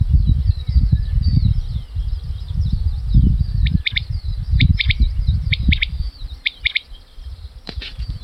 Птицы -> Курообразные ->
перепел, Coturnix coturnix
СтатусПоёт